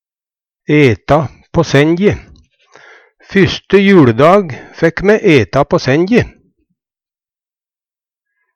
eta på senje - Numedalsmål (en-US)